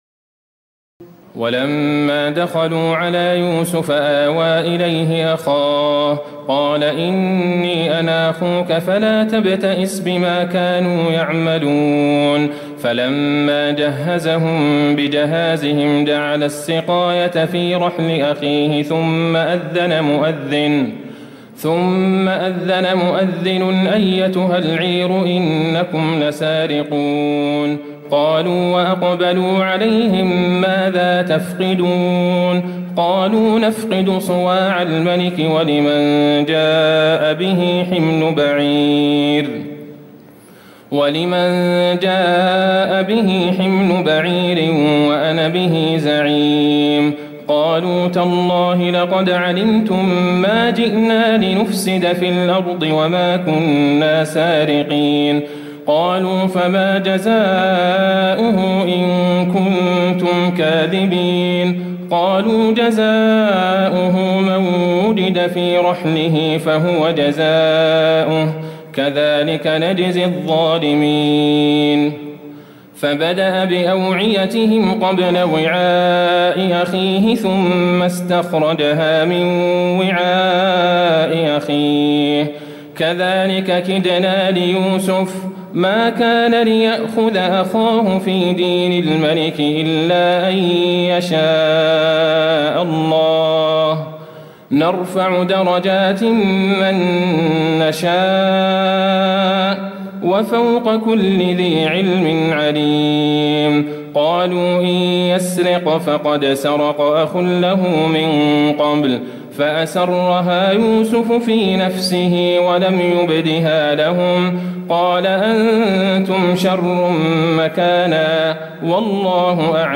تراويح الليلة الثانية عشر رمضان 1436هـ من سور يوسف (69-111) و الرعد (1-43) وإبراهيم (1-8) Taraweeh 12 st night Ramadan 1436H from Surah Yusuf and Ar-Ra'd and Ibrahim > تراويح الحرم النبوي عام 1436 🕌 > التراويح - تلاوات الحرمين